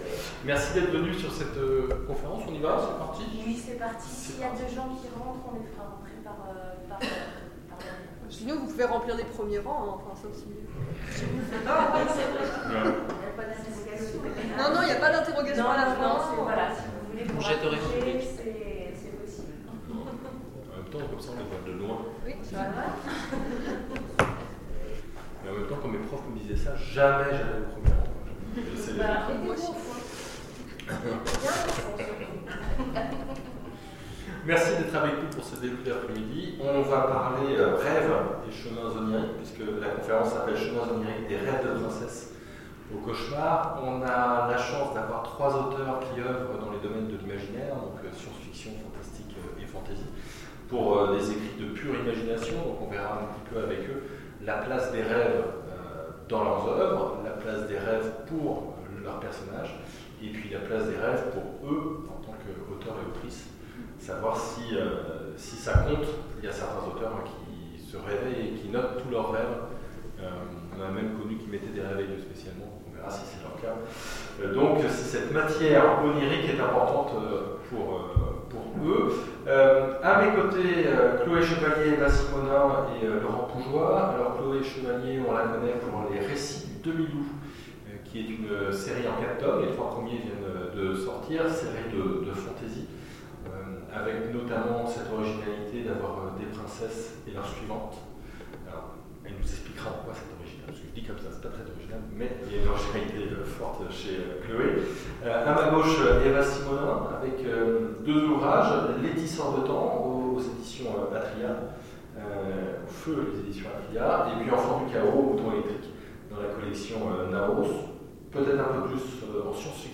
Saint-Marcellin 2018 : conférence Chemins oniriques